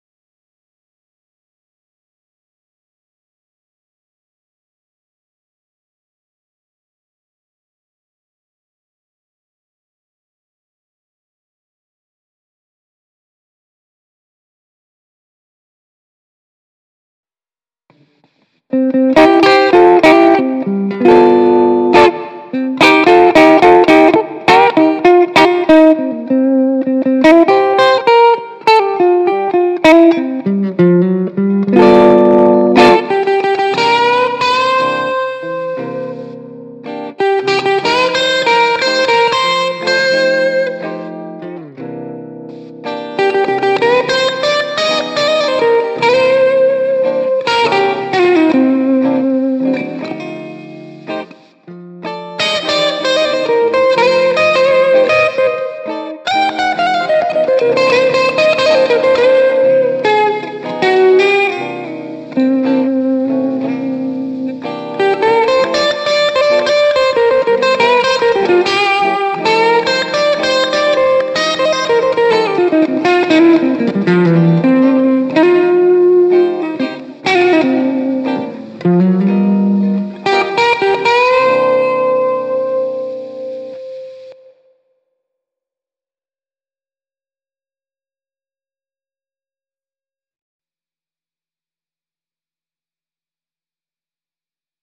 It’s very rough around the edges as it was done in about twenty minutes, it hasn’t been mastered at all and I’ve left all of the mistakes in, including about 20 seconds of silence at the start.
Blues jam C (mp3)
Something bluesy.
I settled on the “sparkling clean” amp as it’s quite versatile: with a low gain it gives a lovely bright clean tone and when you crank it up a notch, it breaks in a nice bluesy crunch.
I laid down three tracks. One for the introduction in a modest crunch, a few chords in a clean tone to provide rhythm backing for a third solo track, which had the gain turned right up and a fair bit more reverb.